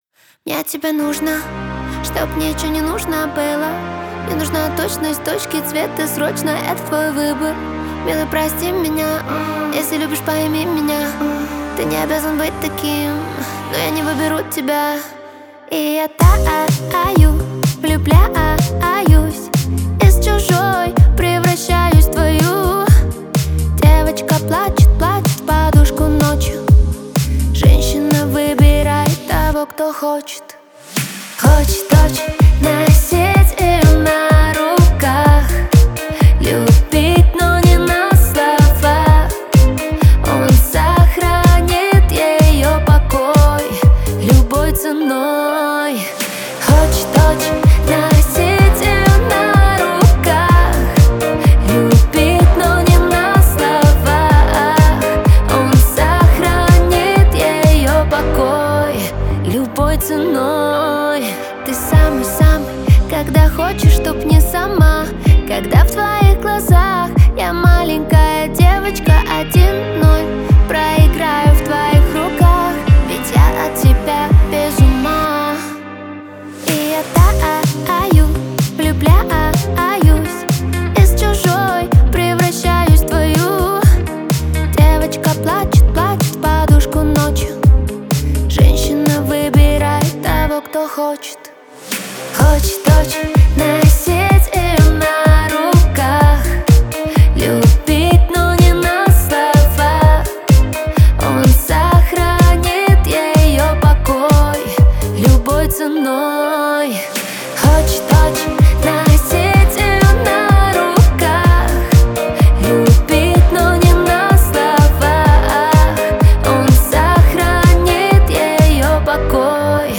Рейв